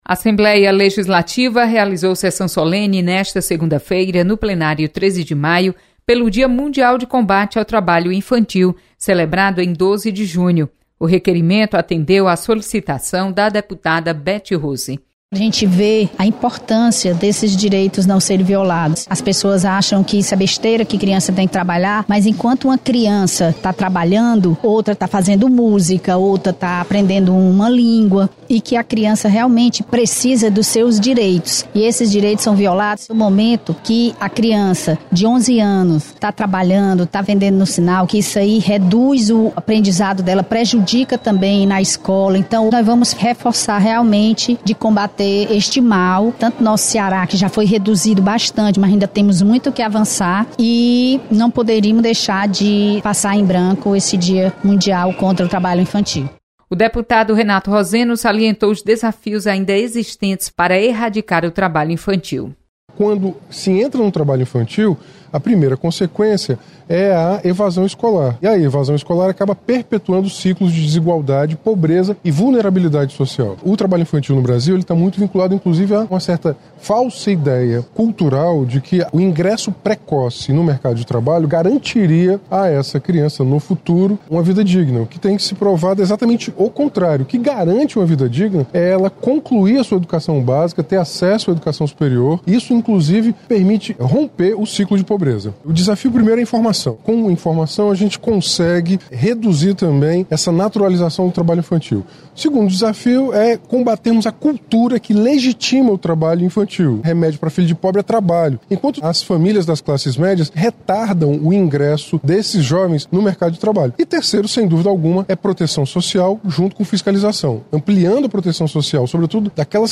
Solenidade